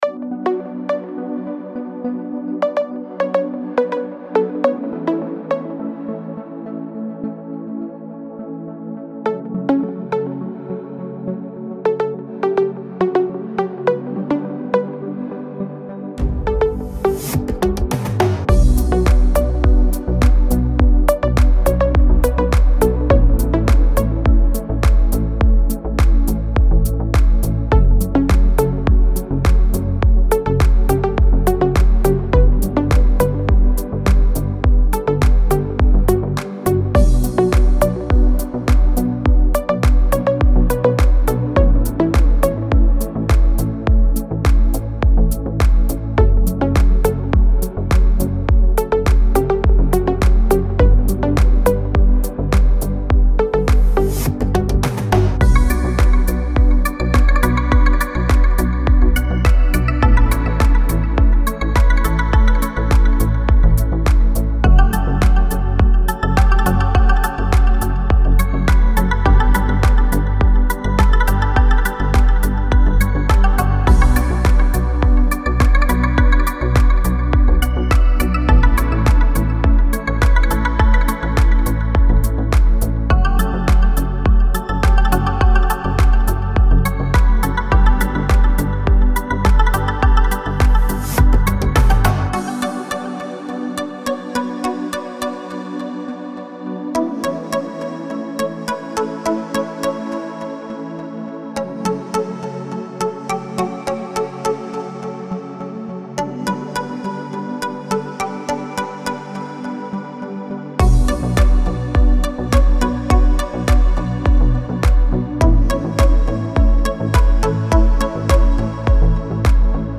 موسیقی بی کلام دیپ هاوس موسیقی بی کلام ریتمیک آرام